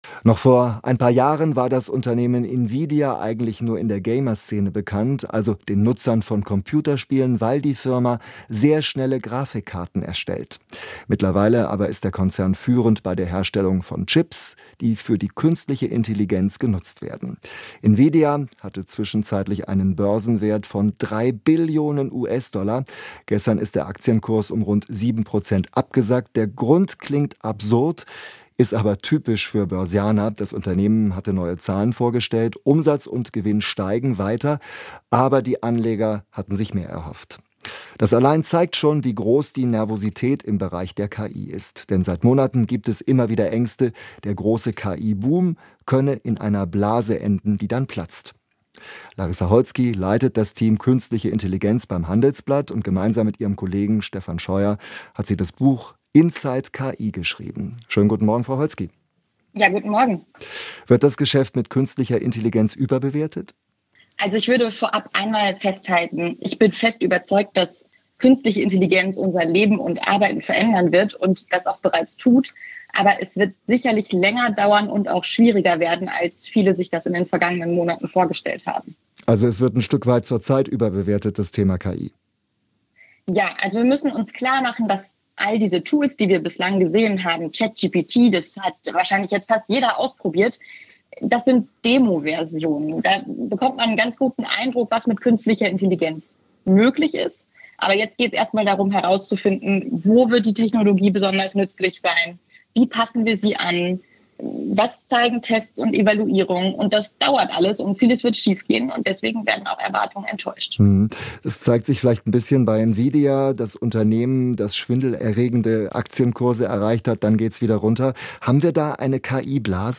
3. Radio
ki-expertin-zu-warnungen-vor-ki-blase-tech-riesen-investieren-weiter.m.mp3